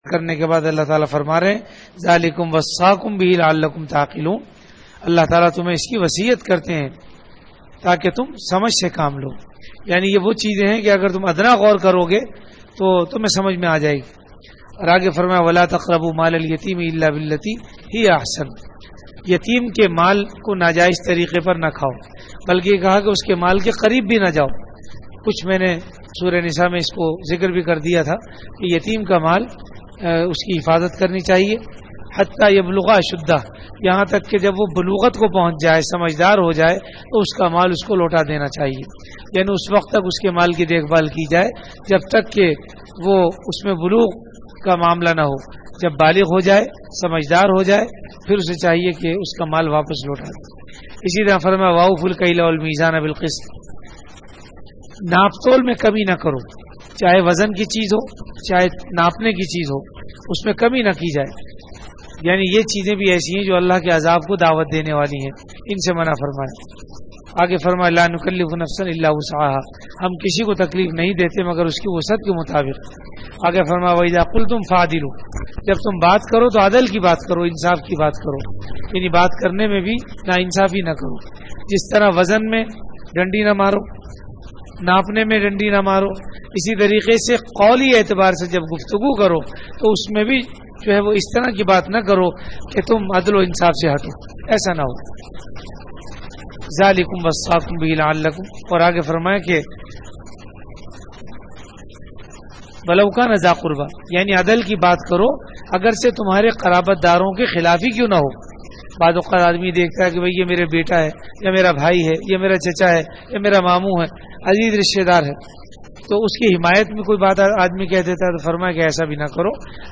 Delivered at Jamia Masjid Bait-ul-Mukkaram, Karachi.
Taraweeh Bayan - Day 9